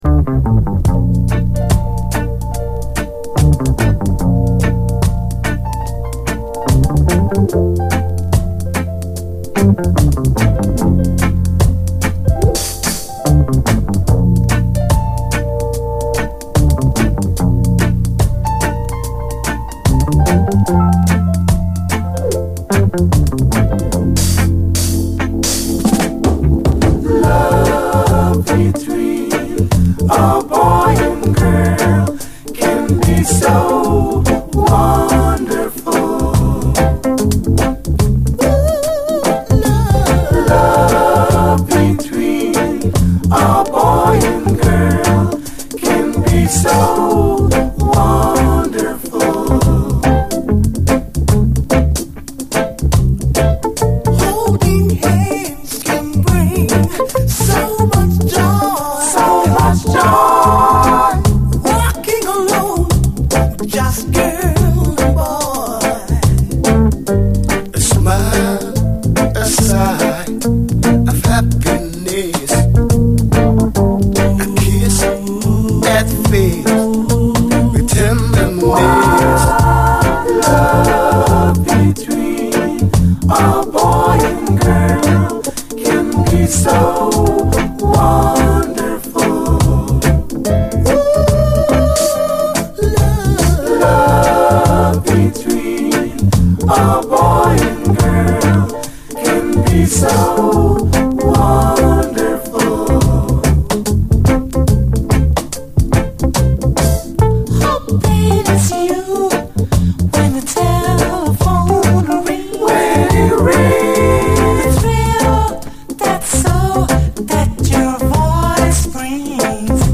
REGGAE
ドリーミー極まる傑作ラヴァーズ！
そして続く超絶コーラス・ハーモニーで息を飲むドリーミー極まる最高ラヴァーズ・チューン！
後半のダブも強烈です！